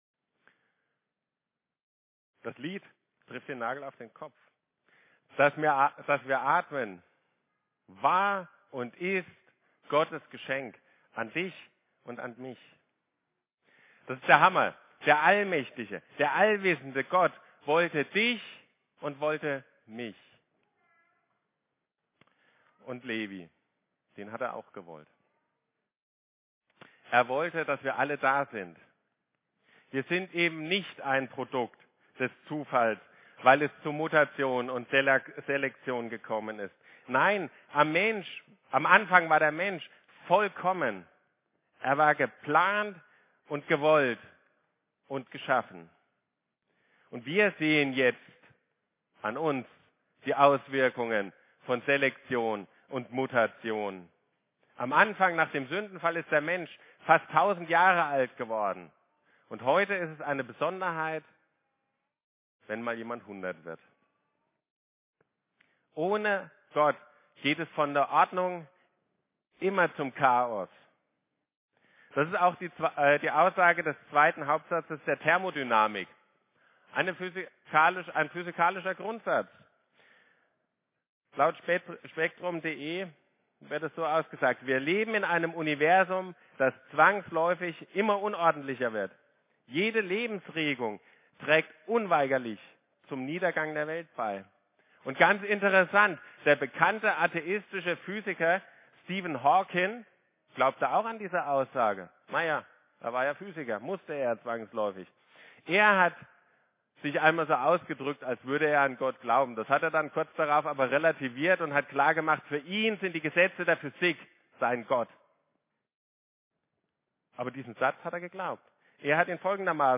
Kindersegnung 27.05.2023
Predigten